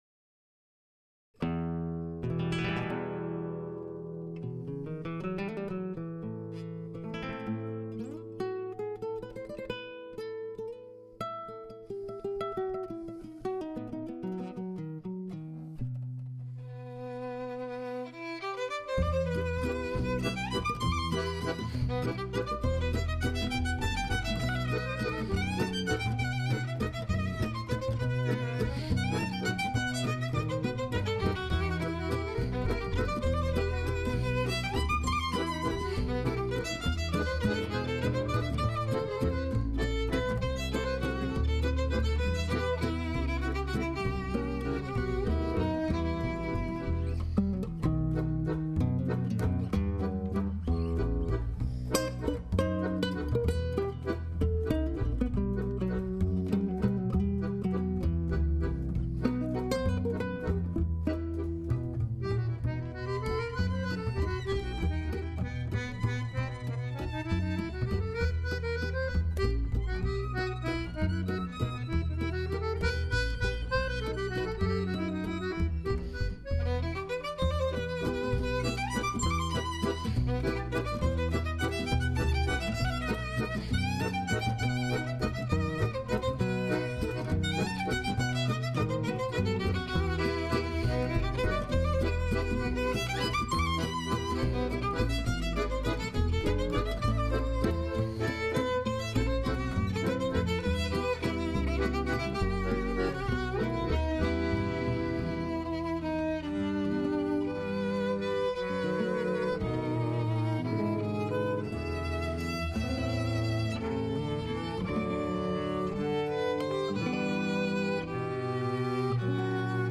Stimmen, Geige, Gitarre, Mandoline und Kontrabass
Swing, Tango, Klezmer und Valse Musette